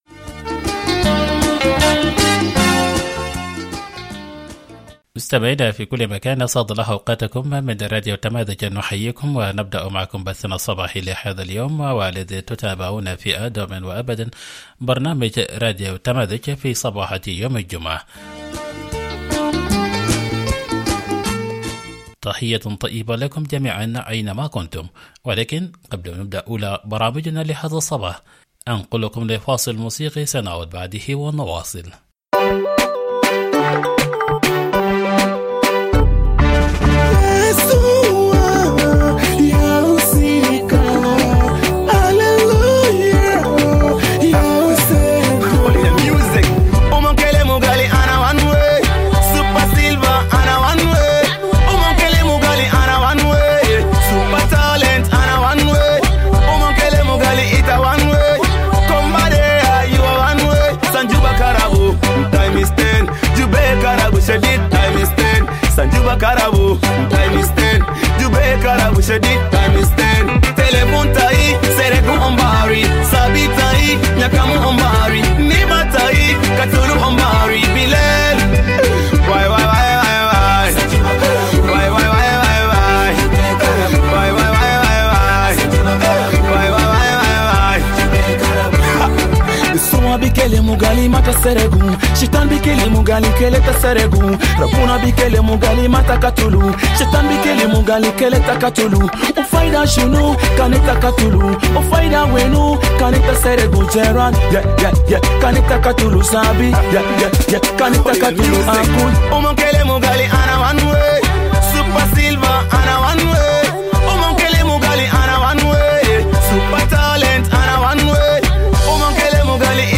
Morning Broadcast 22 July - Radio Tamazuj